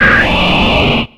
Cri de Laggron dans Pokémon X et Y.